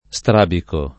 strabico [ S tr # biko ]